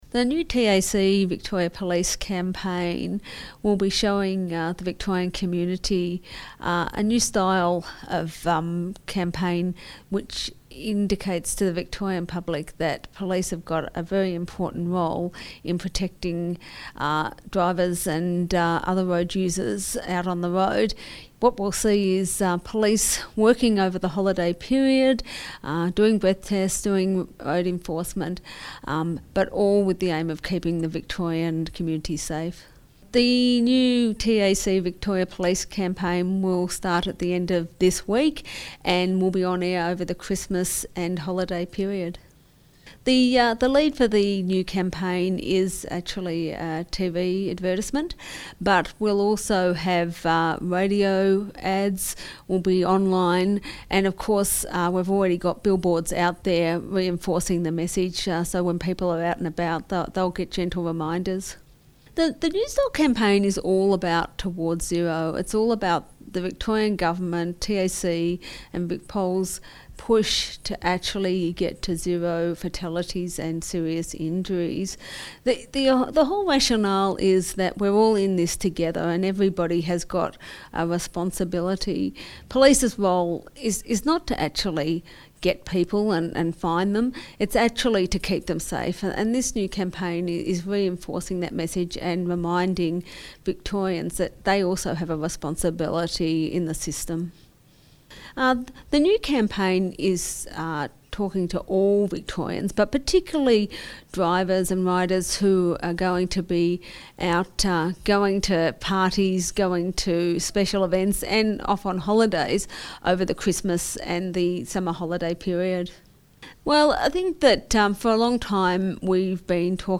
TZ_Holidays_Enforcement_Radio_Grabs.mp3